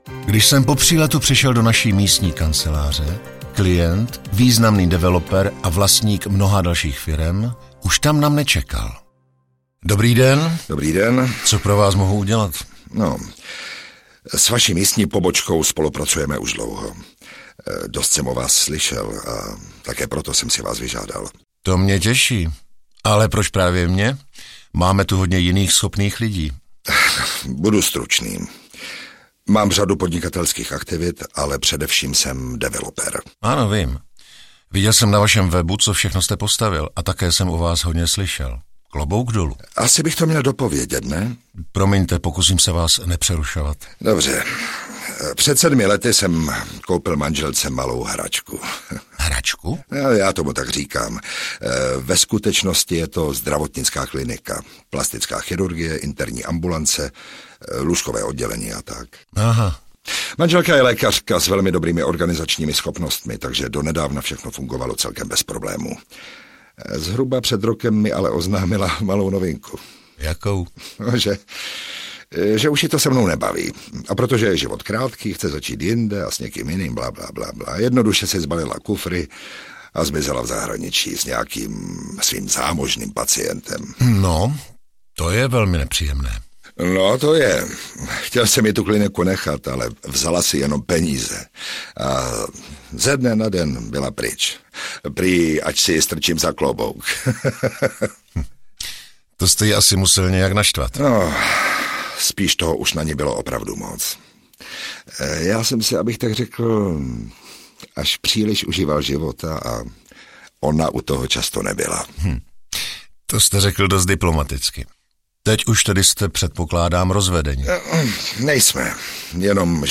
Ukázka z knihy
Spojení zajímavých příběhů s hlasy známých českých herců podtrhuje atraktivitu celého projektu. Šestý díl se odehrává ve zdravotnické klinice. Bohatý developer koupil kliniku manželce, ale ta ho záhy opustila a kliniku mu nechala.
• InterpretAlexej Pyško, Hana Igonda Ševčíková, Miroslav Etzler